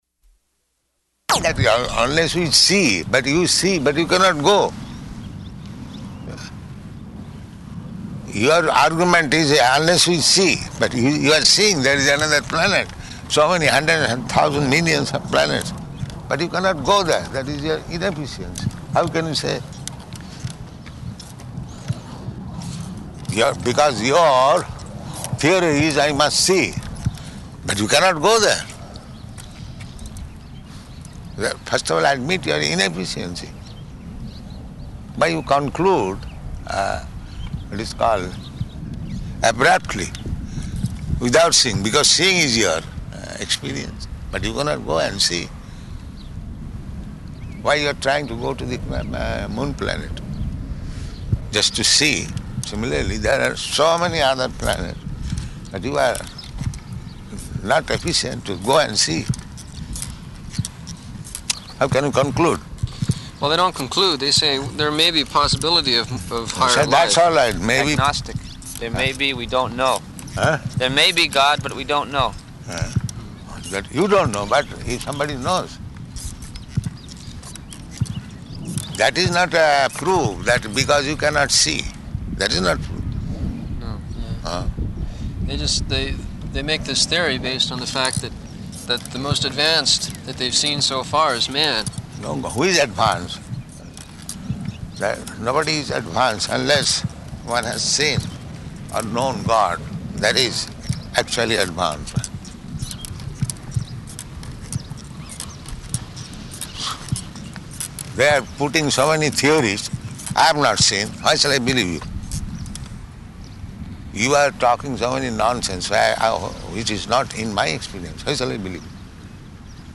Morning Walk --:-- --:-- Type: Walk Dated: January 22nd 1974 Location: Honolulu Audio file: 740122MW.HON.mp3 Prabhupāda: ...that "Unless we see."